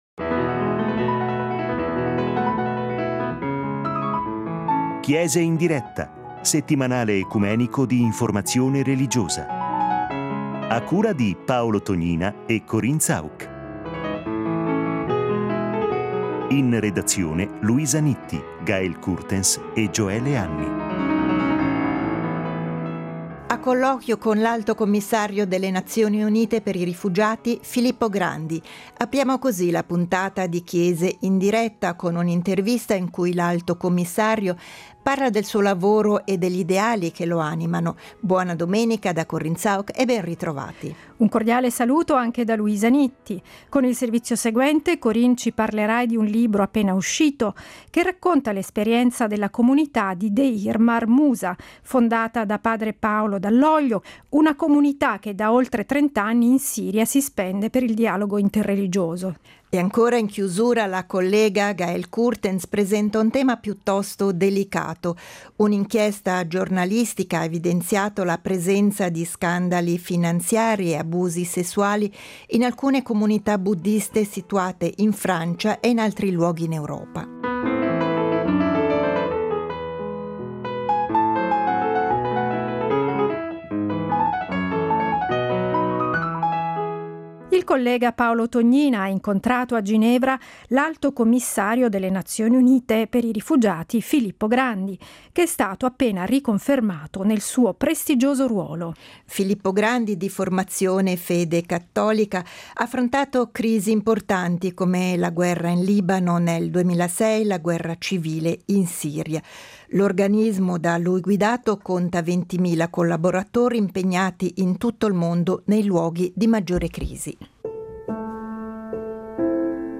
Intervista con Filippo Grandi